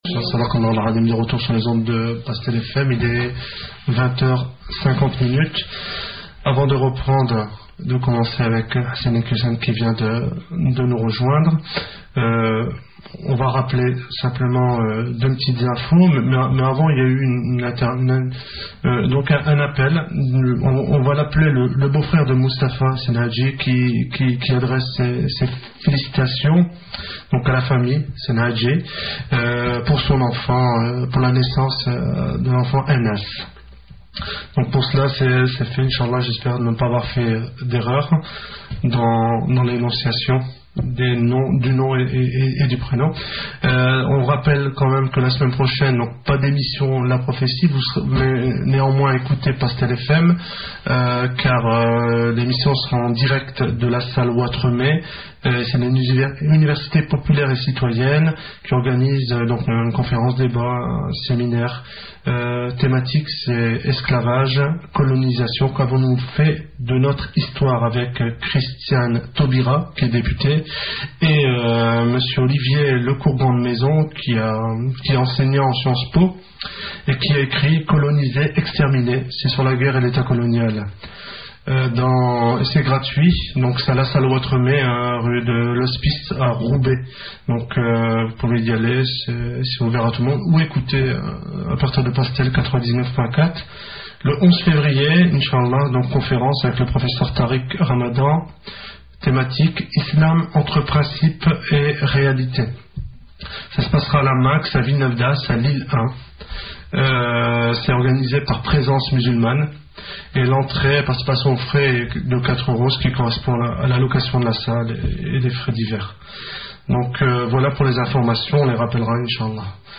Conférence de : Hassan IQUIOUSSEN